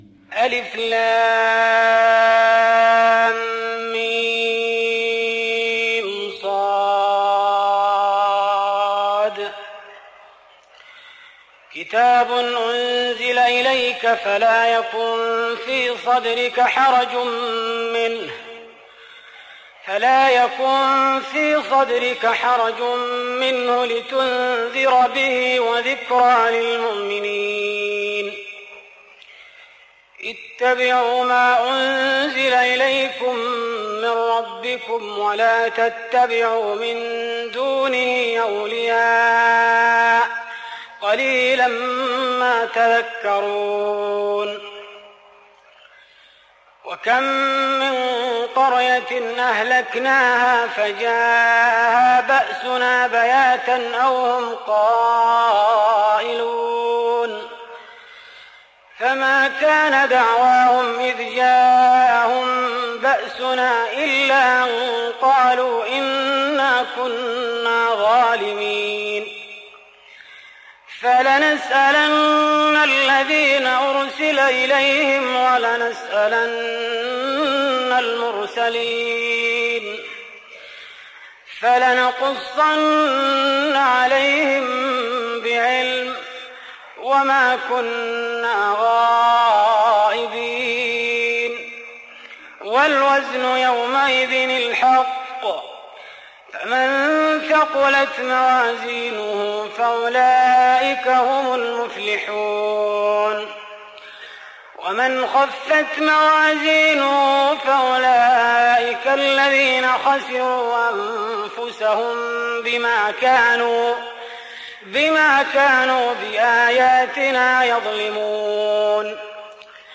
تهجد رمضان 1416هـ فواتح سورة الأعراف (1-87) Tahajjud Ramadan 1416H from Surah Al-A’raf > تراويح الحرم النبوي عام 1416 🕌 > التراويح - تلاوات الحرمين